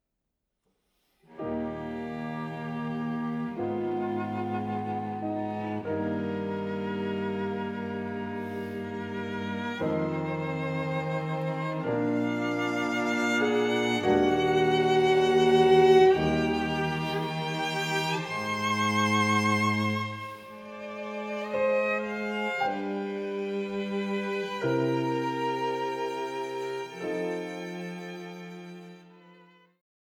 Violine
Viola
Violoncello